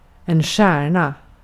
Ääntäminen
IPA: [ˈɧæːɳˌa]
IPA: /ˈɧɛːrˌna/